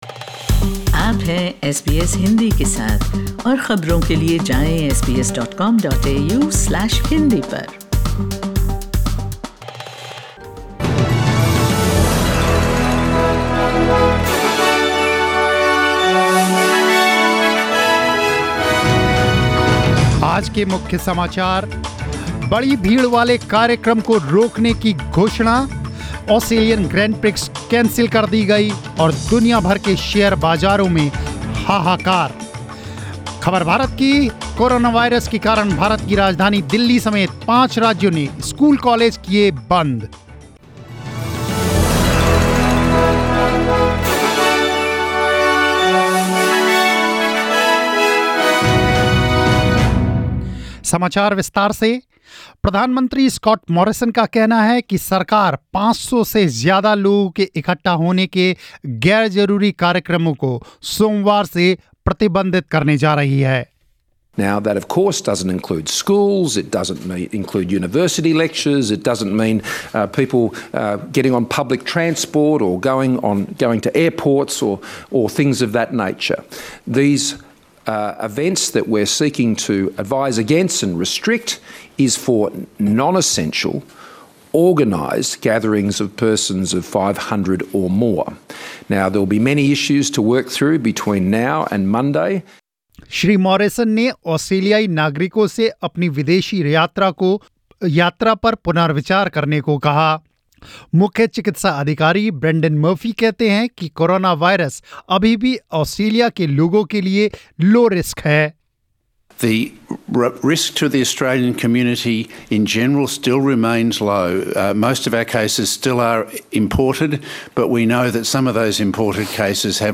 News in Hindi 13th March 2020